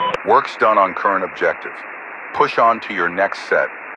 Radio-commandObjectiveComplete4.ogg